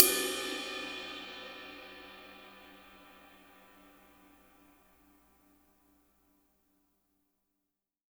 -RIDE AD  -L.wav